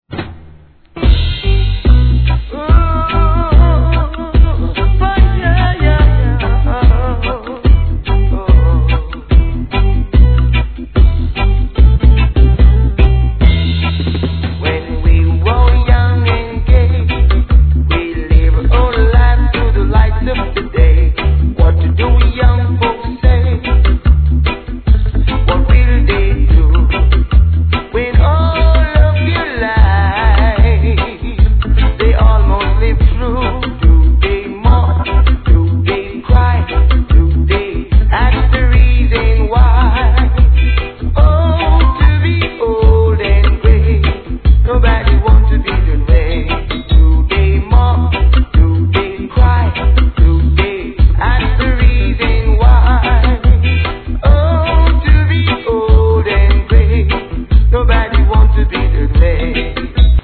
REGGAE
マイナーコードな怒渋ROOTS!